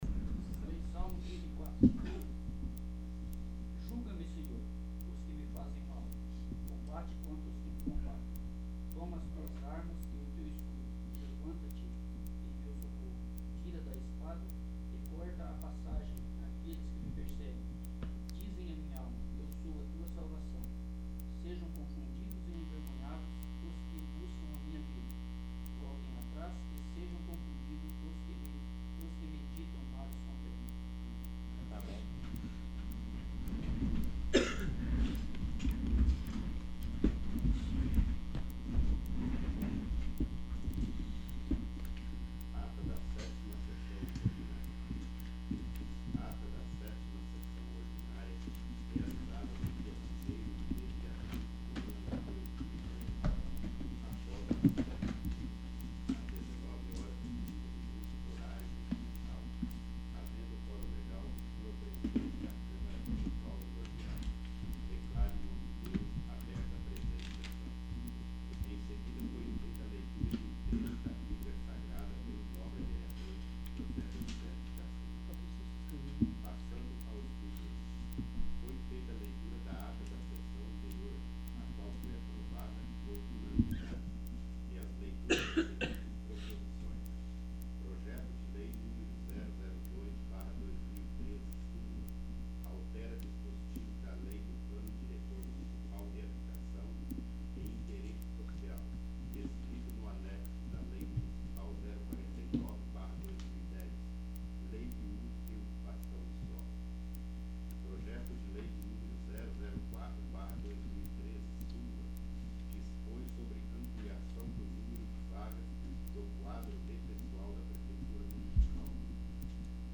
8º. Sessão Ordinária